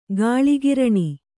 ♪ gāḷi giraṇi